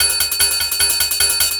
Ride 05.wav